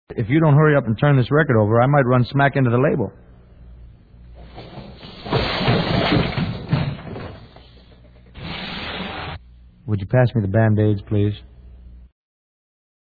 • Mono & Stereo
The album included Bobby's narration and the original Atco yellow harp label.